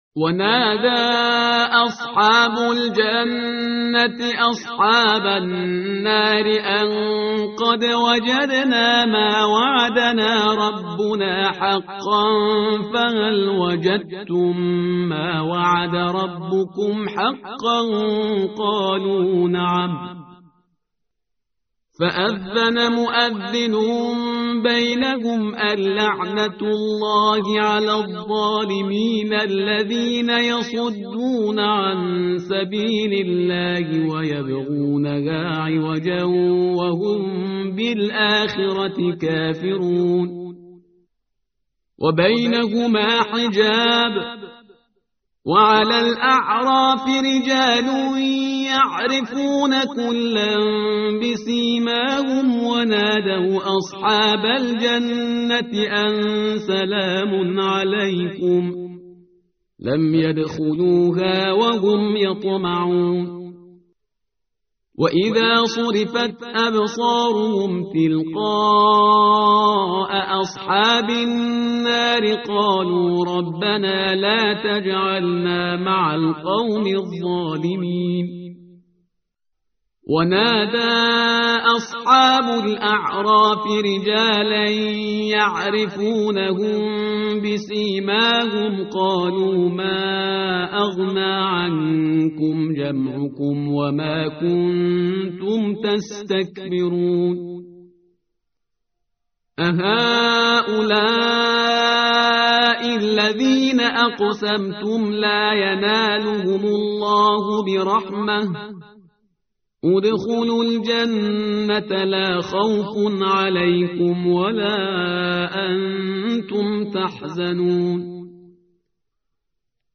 tartil_parhizgar_page_156.mp3